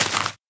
1.21.4 / assets / minecraft / sounds / dig / grass1.ogg
grass1.ogg